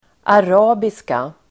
Uttal: [ar'a:biska]